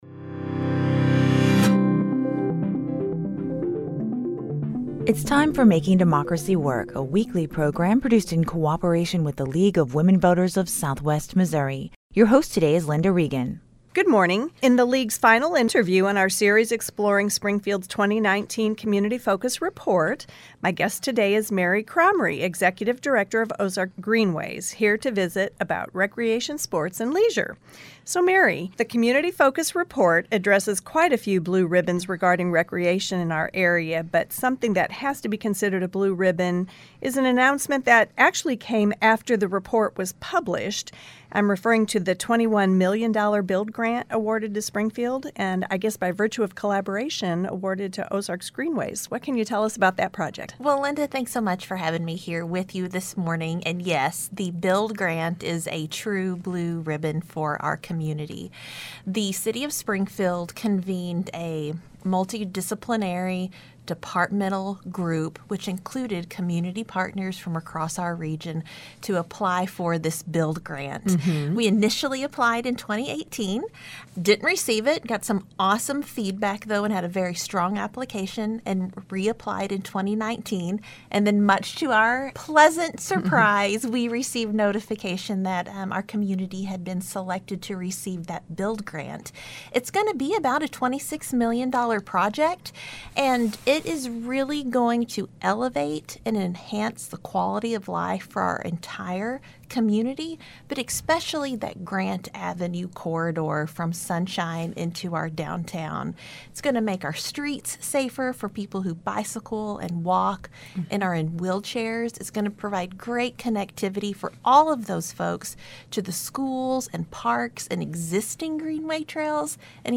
This week on Making Democracy Work, we wrap up our series that dives deeper into individual aspects of the 2019 Springfield-Greene County focus report.  Today's discussion explores Red Flags and Blue Ribbons looking at recreation and green spaces.